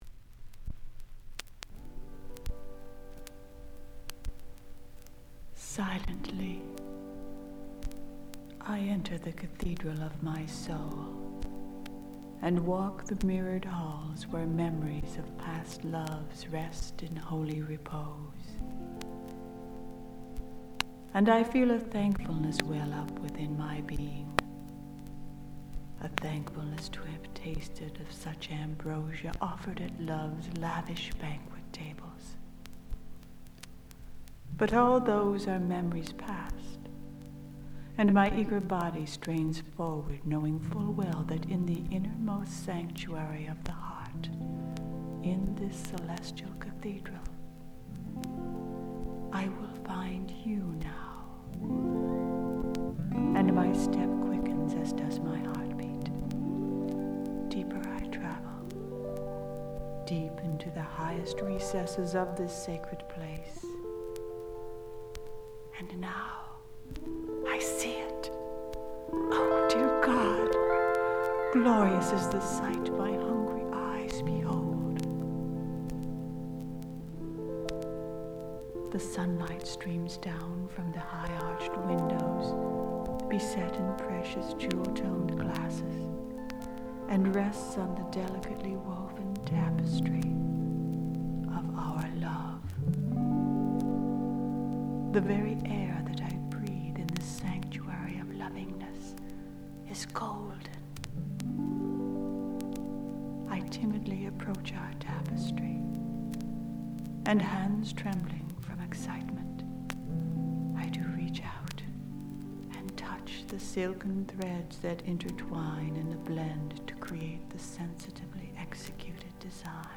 folk-psych